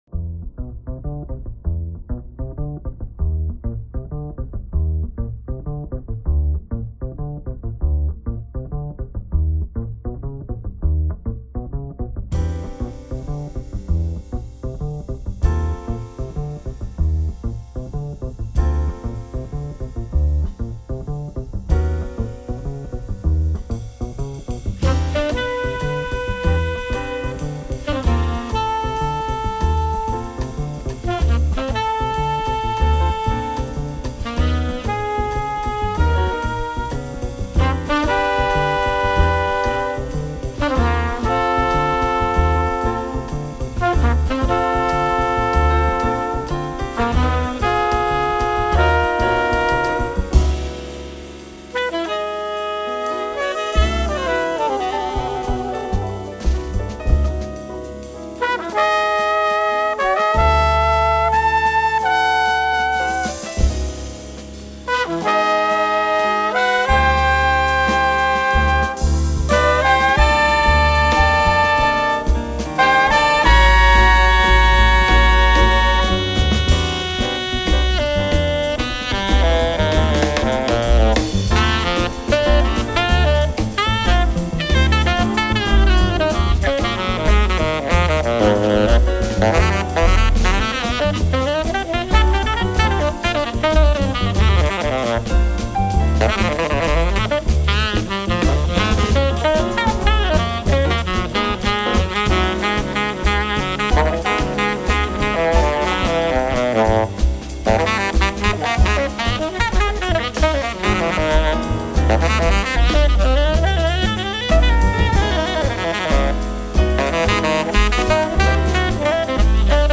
trumpet
tenor & soprano sax
piano
double bass
drums
alto sax
percussion